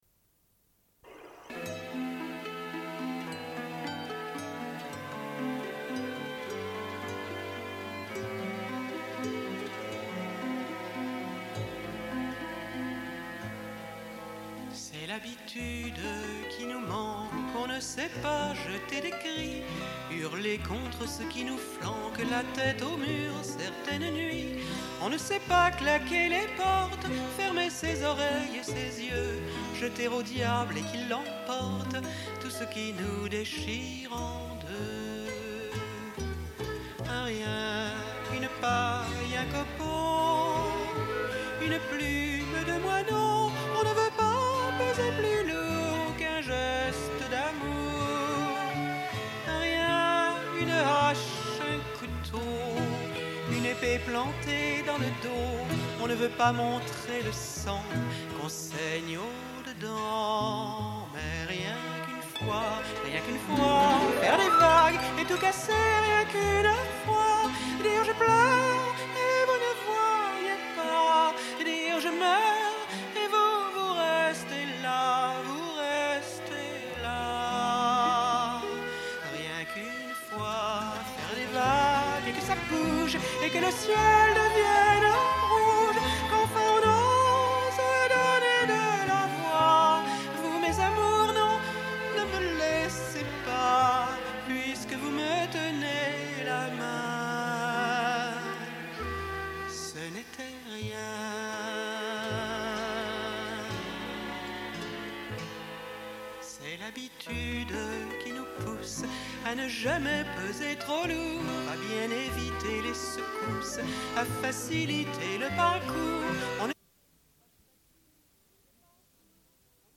Une cassette audio
Radio